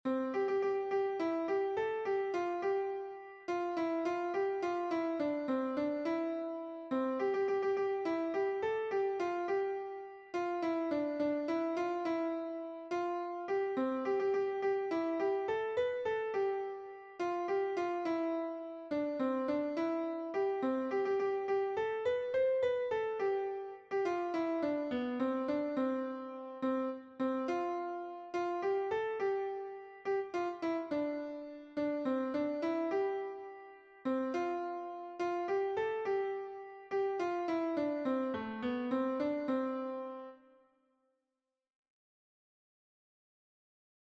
It's a bouncy melody in C Major, 6/8 time.